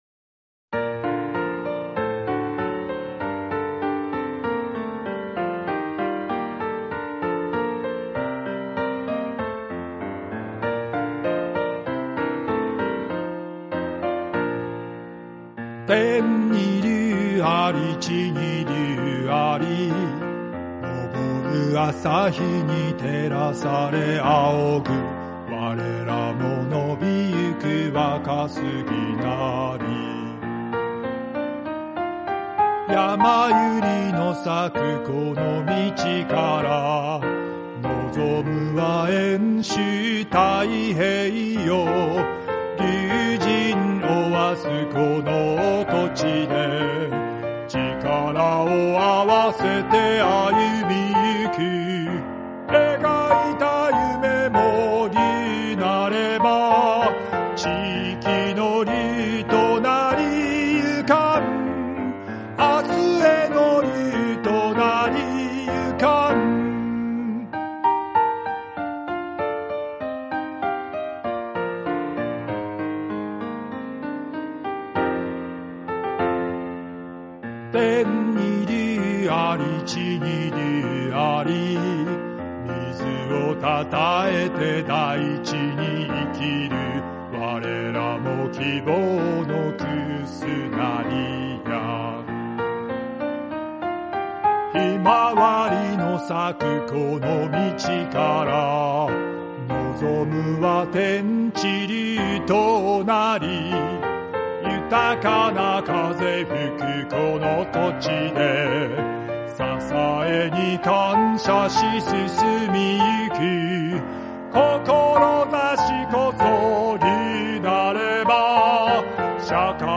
校歌 サンプラザ中野くん歌唱の天竜高校校歌は、こちらから聴けます[MP3：1.12MB]